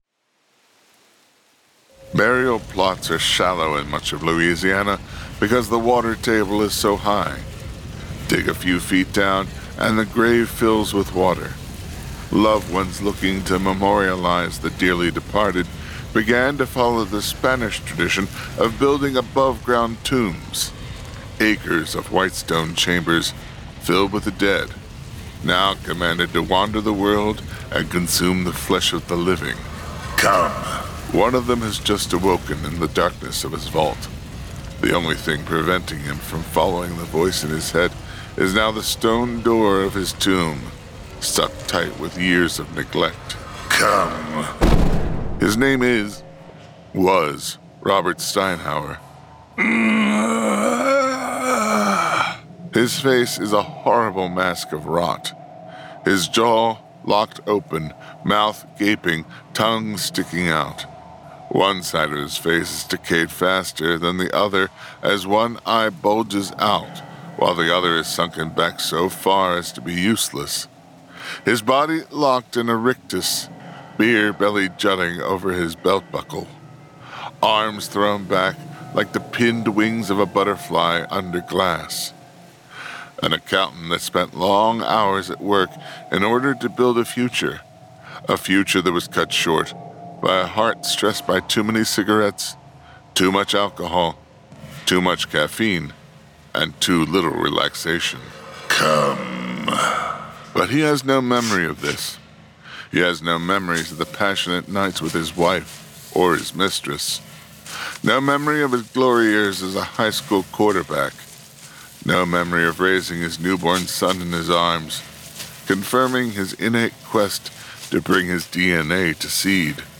Full Cast. Cinematic Music. Sound Effects.
[Dramatized Adaptation]
Genre: Horror